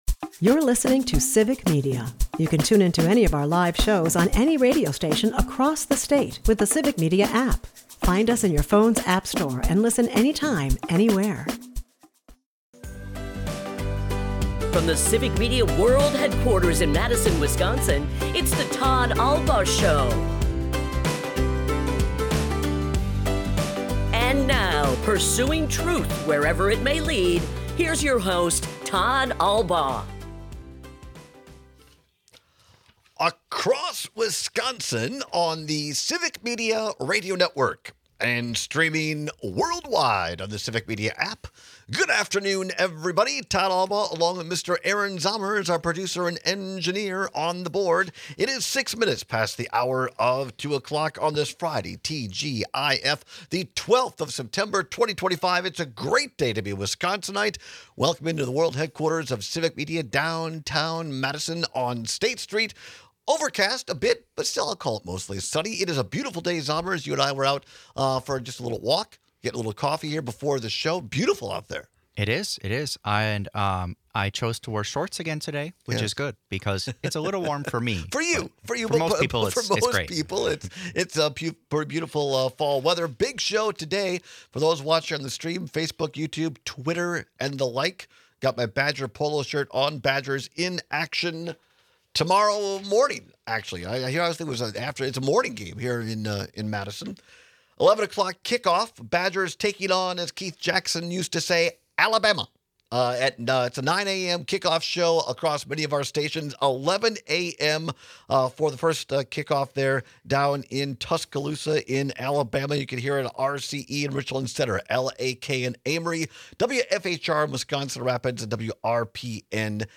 joins us live in-studio for the first hour!